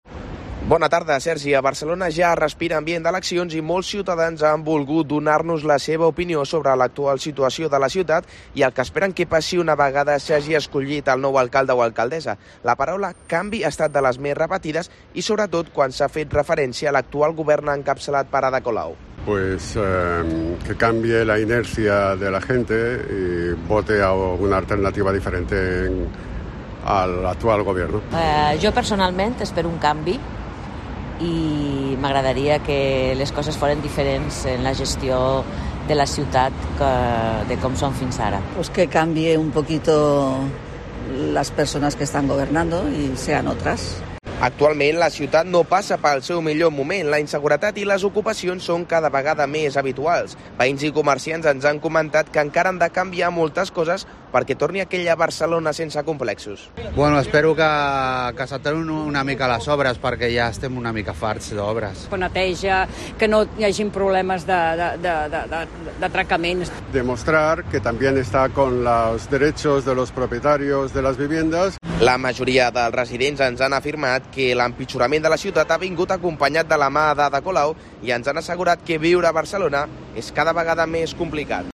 Preguntamos a los vecinos de Barcelona qué esperan de las elecciones del 28 de mayo y qué cosas querrían que cambiasen.